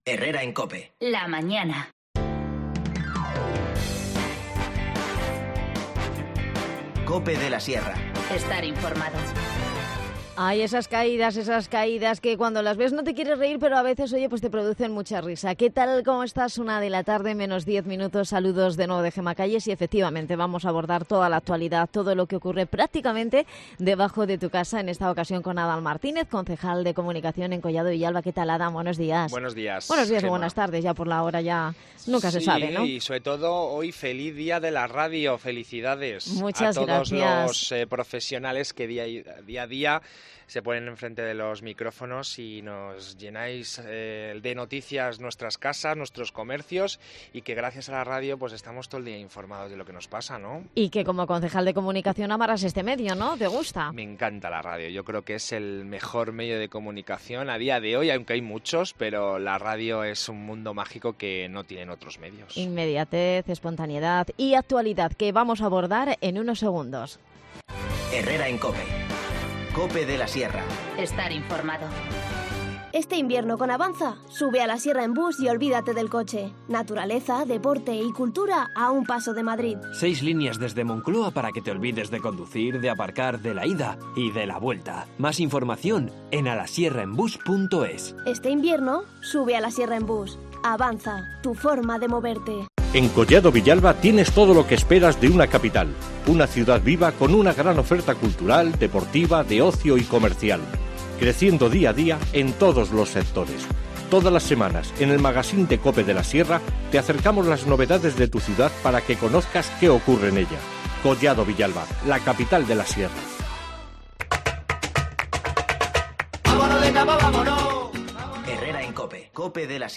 Redacción digital Madrid - Publicado el 13 feb 2020, 13:11 - Actualizado 16 mar 2023, 17:58 1 min lectura Descargar Facebook Twitter Whatsapp Telegram Enviar por email Copiar enlace Nos visita Adan Martínez, concejal de comunicación en Collado Villalba, para abordar toda la actualidad del municipio